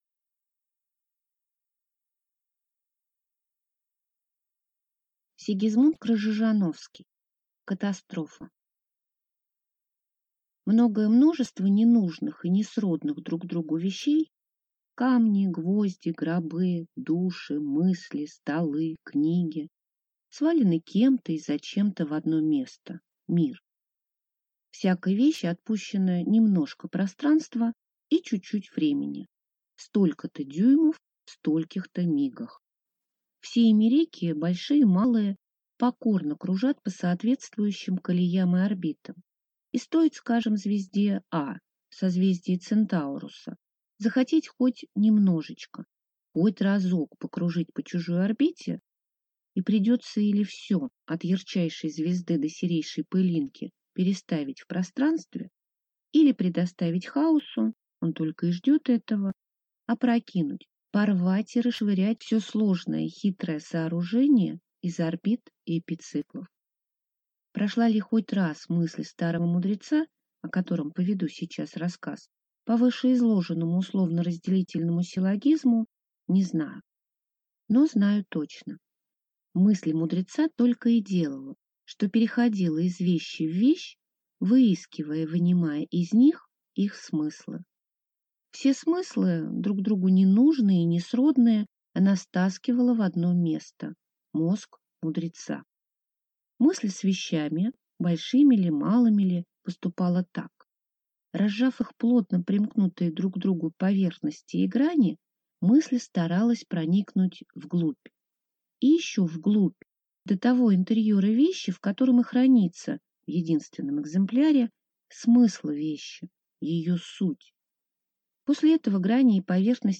Аудиокнига Катастрофа | Библиотека аудиокниг